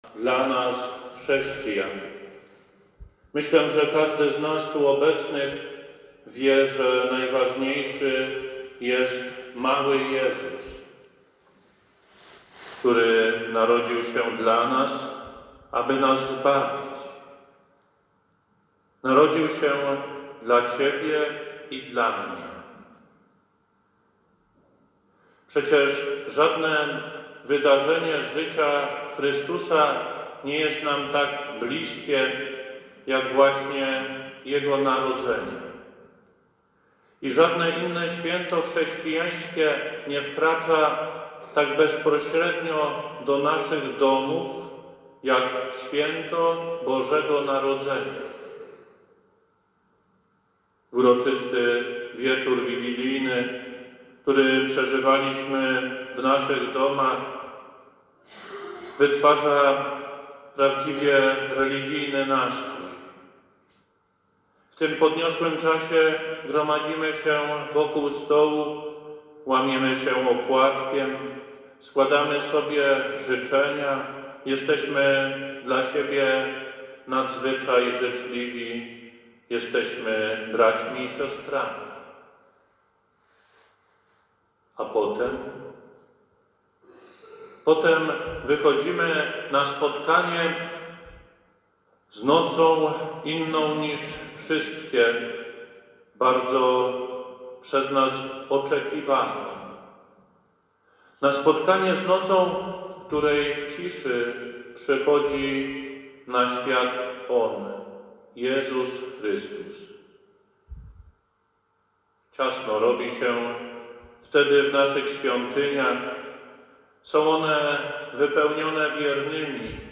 KAZANIA DO SŁUCHANIA - OKOLICZNOŚCIOWE